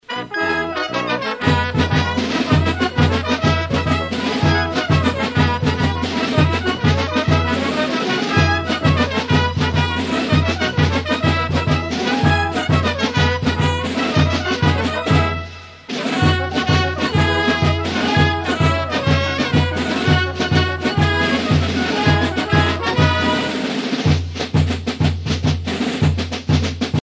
carnaval, mardi-gras
Pièce musicale éditée